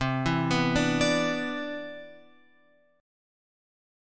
C Major 9th